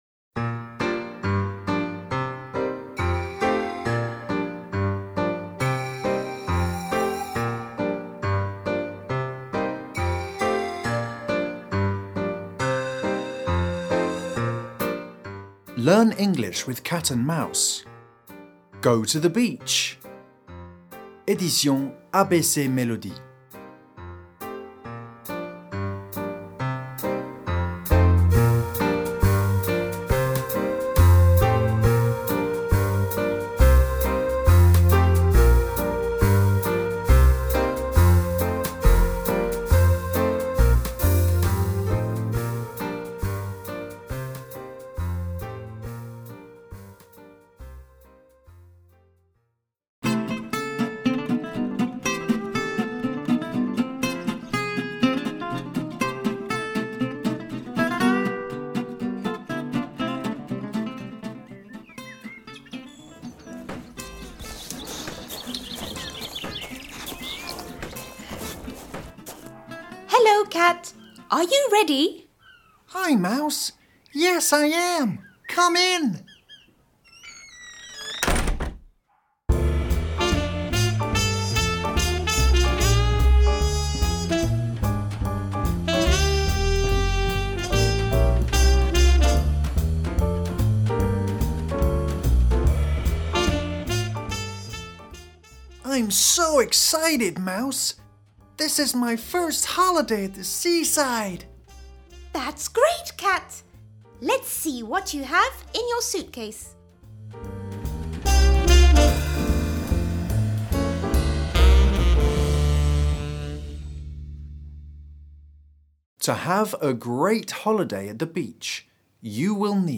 LEARN ENGLISH WITH CAT AND MOUSE, une collection en 3 niveaux pour apprendre l’anglais en s’amusant de la Maternelle au CM, avec l’audio accessible par QR Code dans le livre: l’histoire et des jeux audio pour bien prononcer !